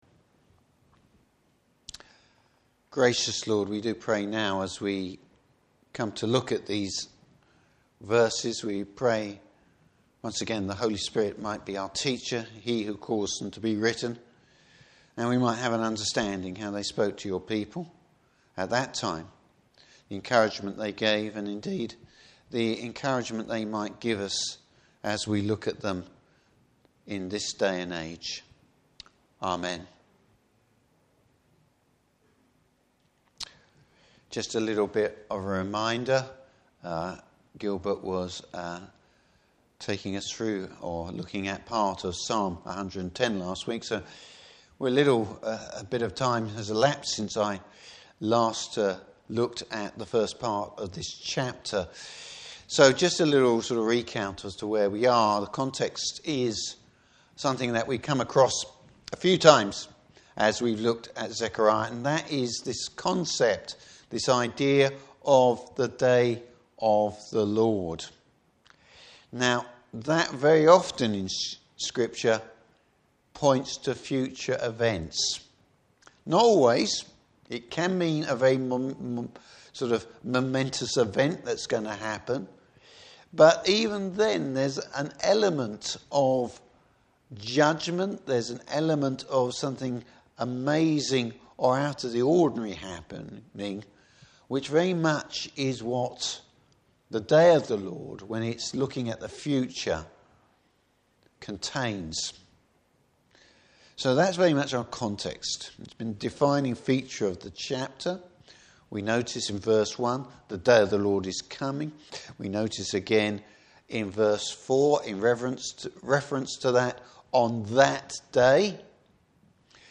Service Type: Evening Service What is ‘living water?’